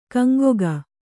♪ kaŋgoga